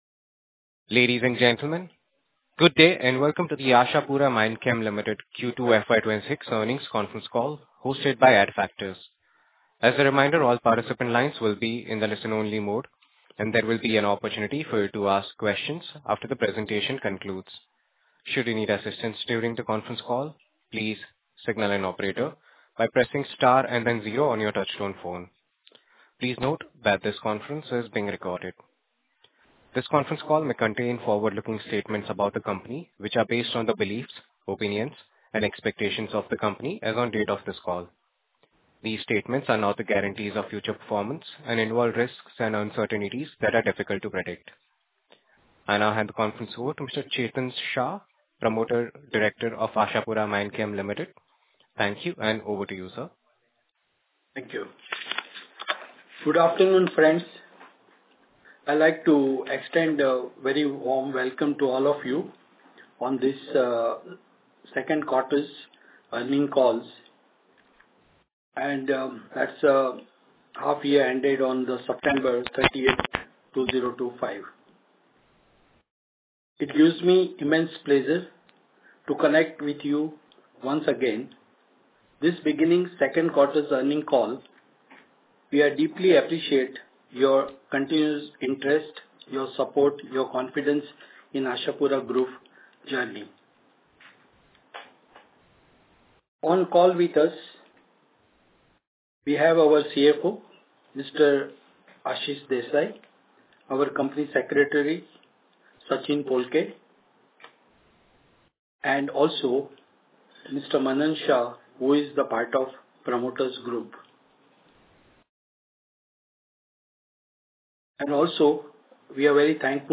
Concalls
Ashapura Q2 FY26 Earning calls Audio Recording.mp3